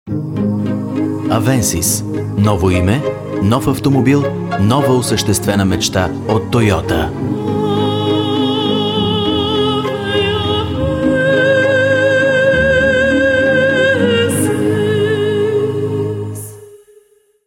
Toyota Avensis radio commercial spot